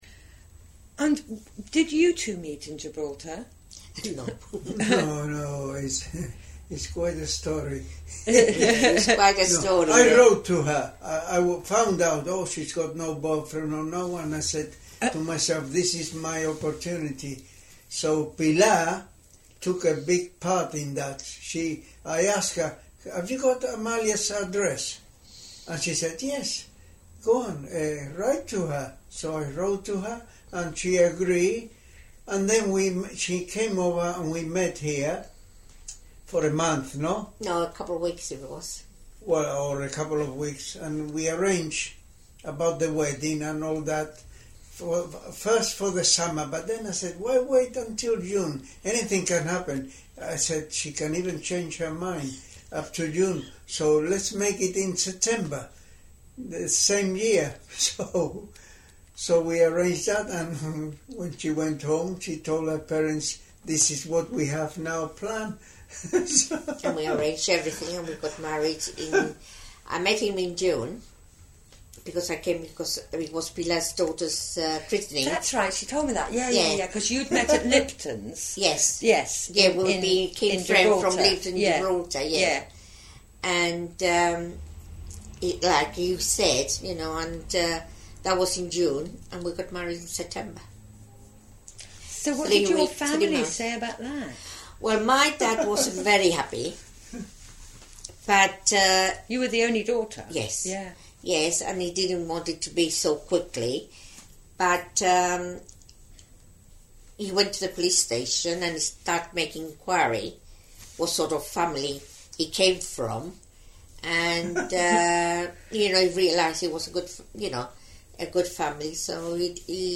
An interesting interview which covers evacuation, repatriation, Gibraltarian and British culture.